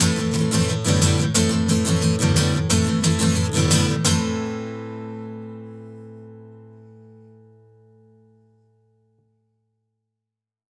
Acoustic Guitar 05.wav